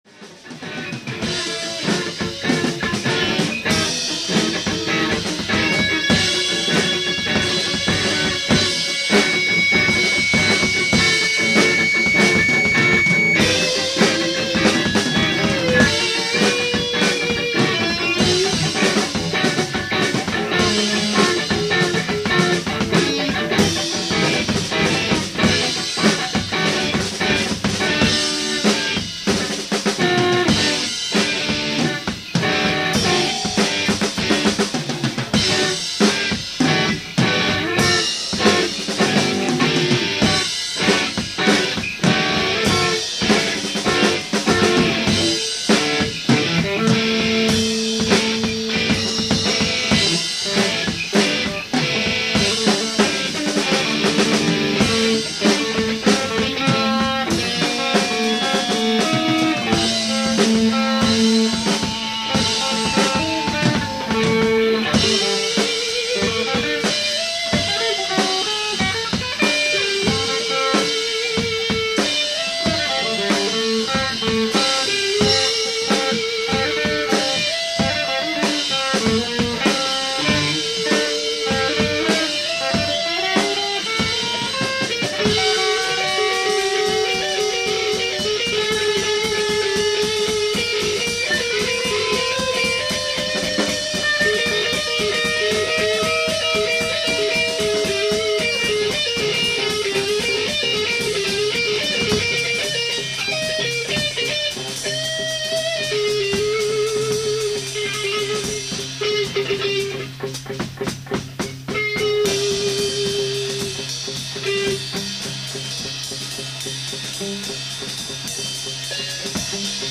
スタジオ音源で自慢になりそうでならないものを載せていこうと思います。
サンプラー
ギター
即興１　：　早弾き万歳